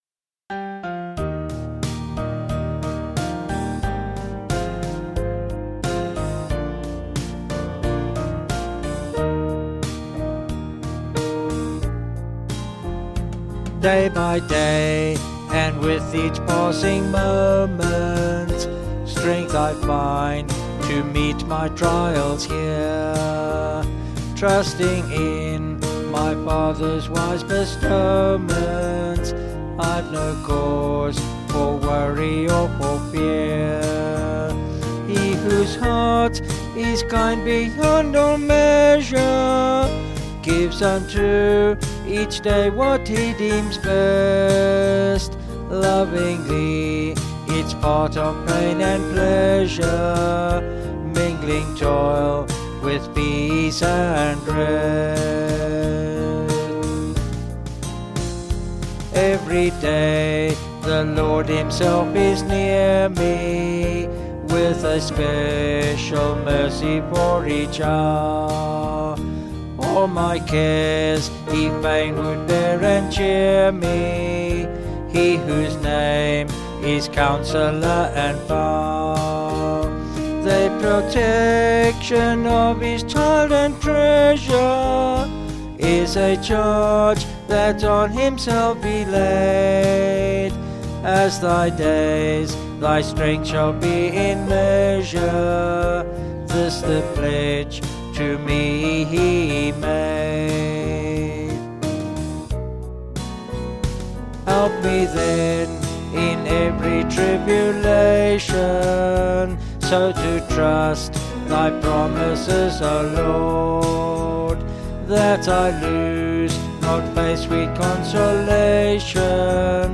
Vocals and Band   264.9kb Sung Lyrics